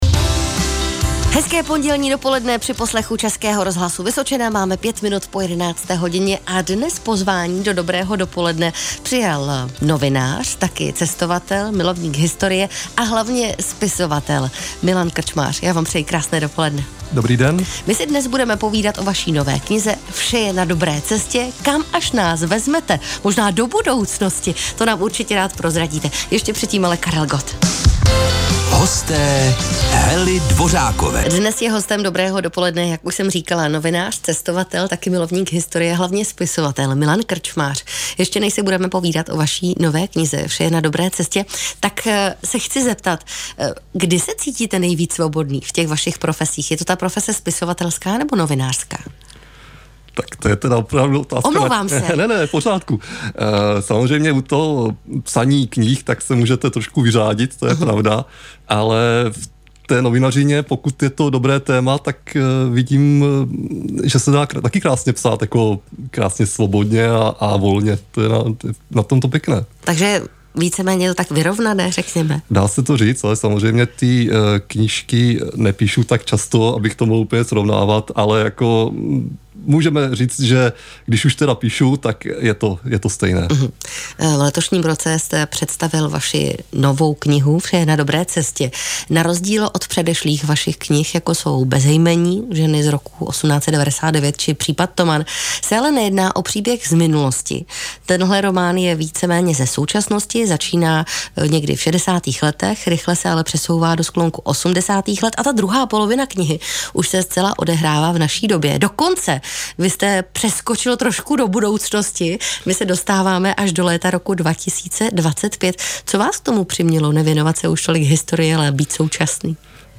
Rozhovor o knize na ČRo Region Vysočina zde.